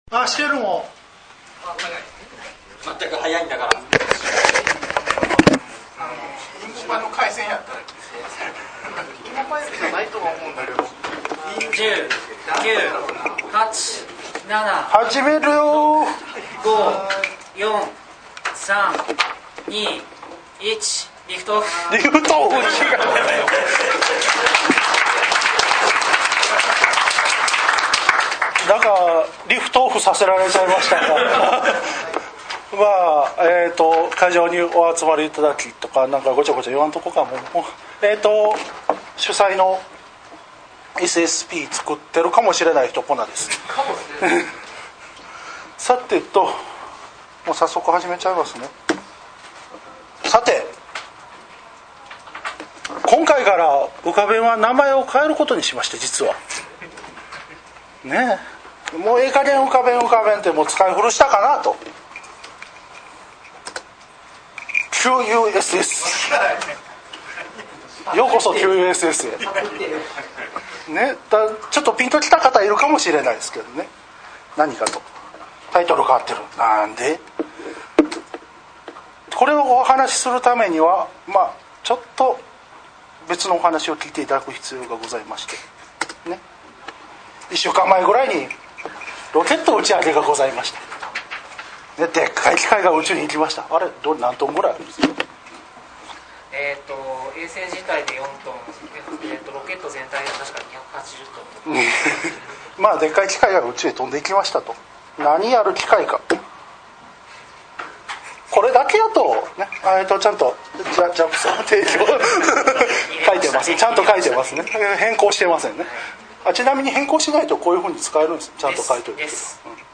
スライド(PowerPoint等プレゼンツール)を使った講演が主となります。